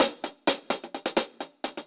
Index of /musicradar/ultimate-hihat-samples/128bpm
UHH_AcoustiHatB_128-03.wav